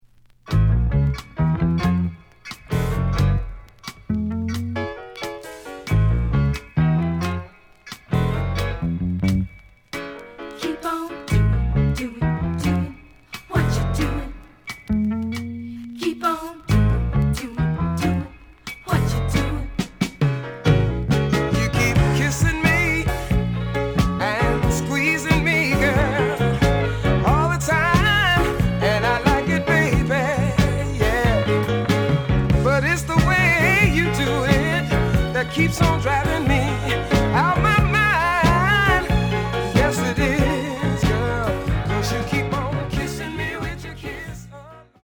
(Mono)
The audio sample is recorded from the actual item.
●Genre: Soul, 70's Soul
Slight edge warp.